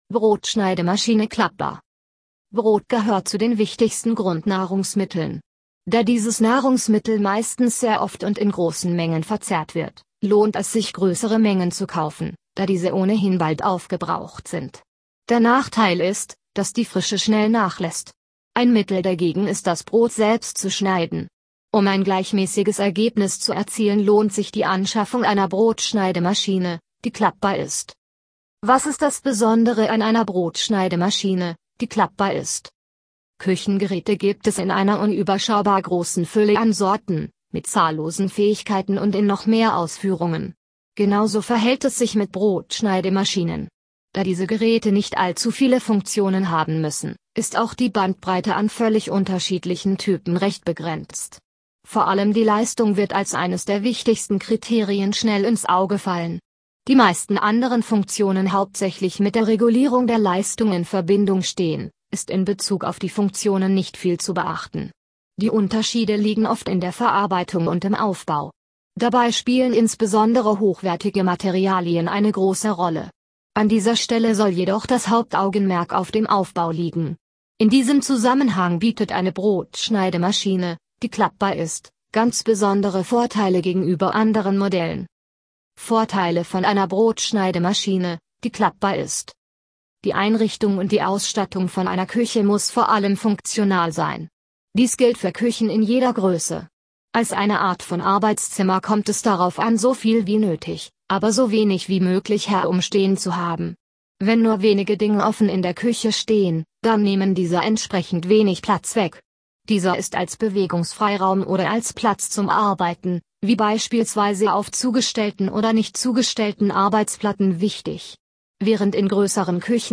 (Audio für Menschen mit Seh- oder Leseschwäche – Wir lesen Ihnen unseren Inhalt vor!)